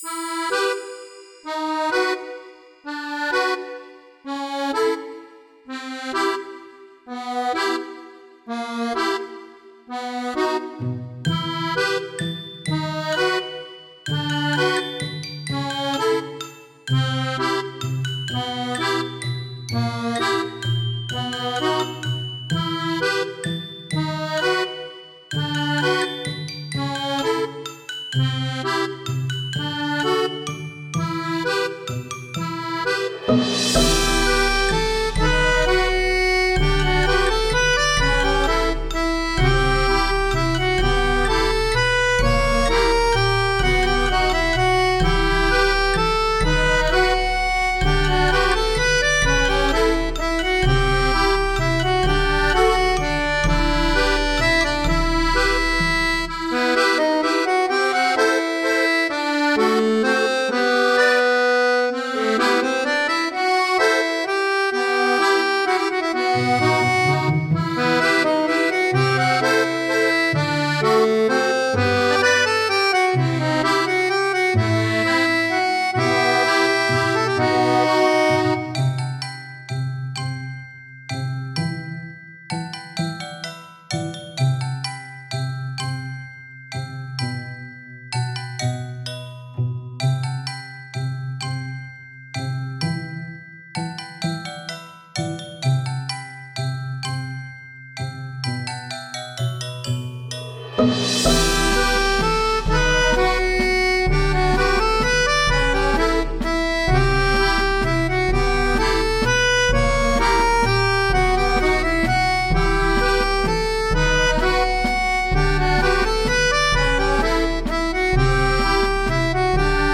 フリーBGM素材- ほんのり大正感がなくもない、ミステリー風。